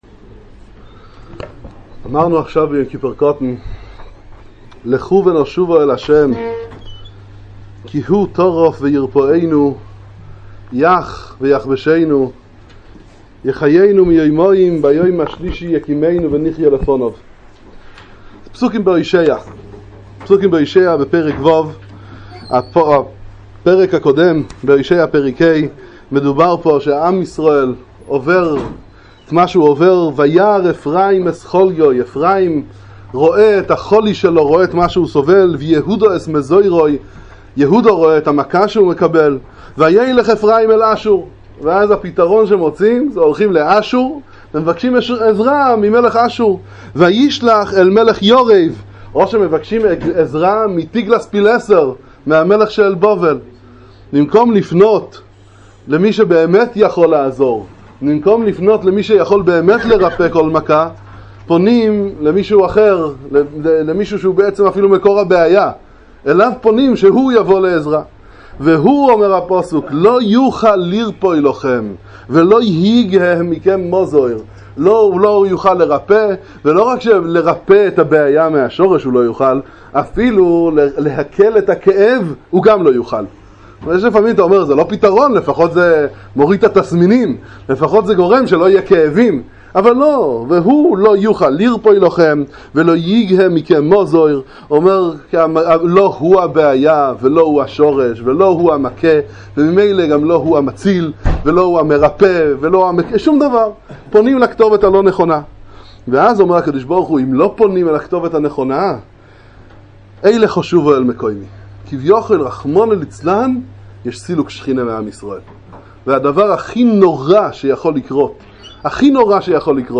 הספד על מרן רבי חיים קניבסקי זללה"ה